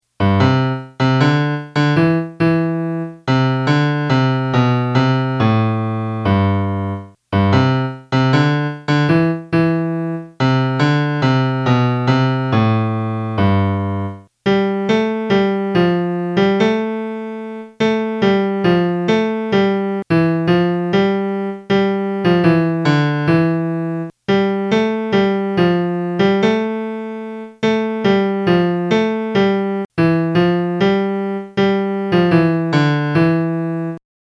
di-qua-di-la-del-piave-melody.mp3